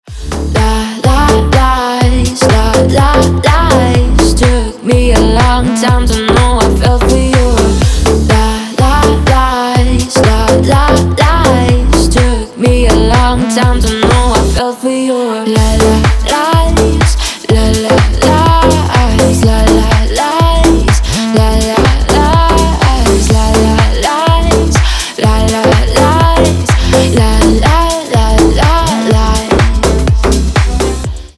• Качество: 320, Stereo
ритмичные
басы
красивый женский голос
Стиль: house